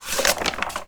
bp_box_open2.wav